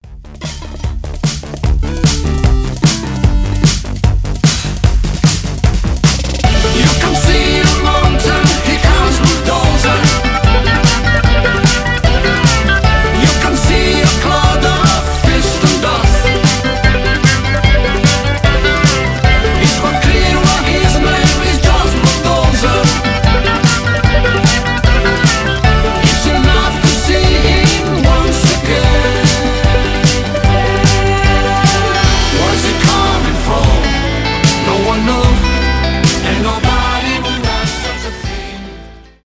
фанк
зажигательные
танцевальные , диско